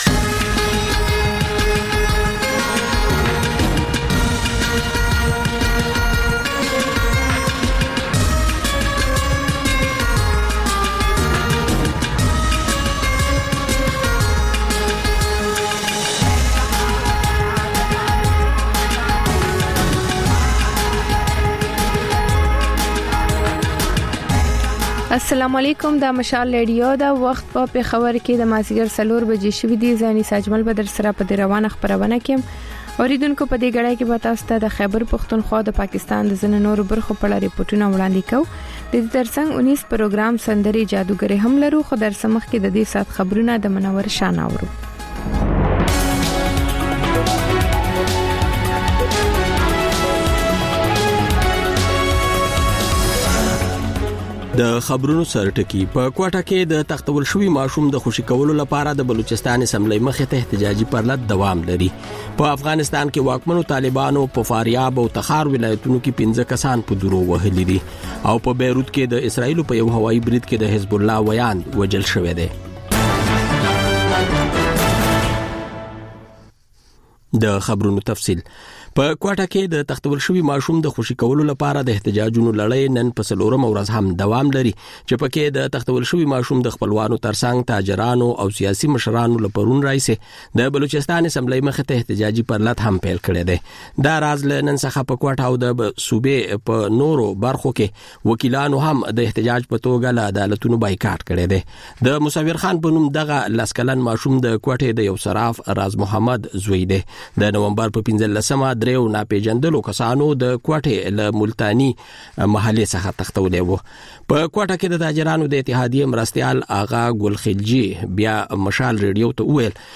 د مشال راډیو مازیګرنۍ خپرونه. د خپرونې پیل له خبرونو کېږي، ورسره اوونیزه خپرونه/خپرونې هم خپرېږي.